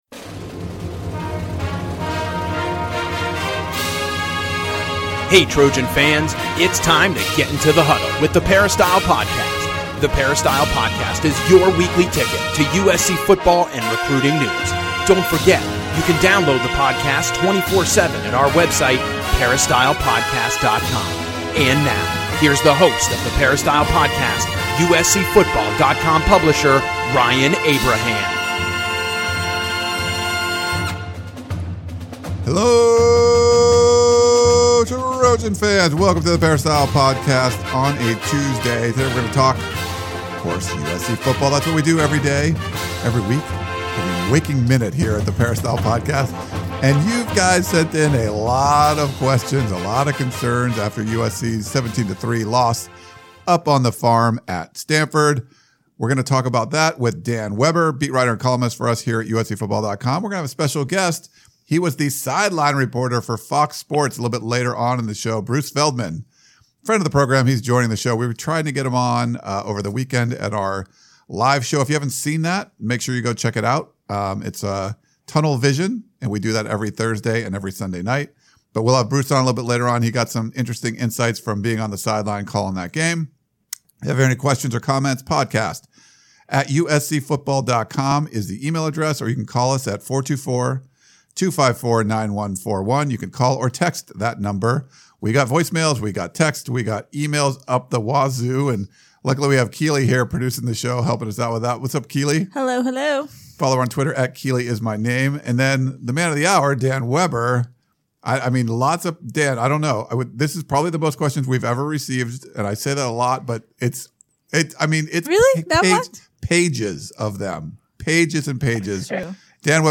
We also have special guest Bruce Feldman joining us in studio to talk about what he saw up on The Farm.